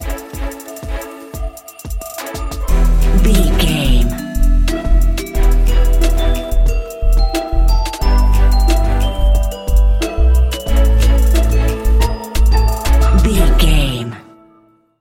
Aeolian/Minor
Slow
eerie
groovy
dark
synthesiser
drum machine
sleigh bells
strings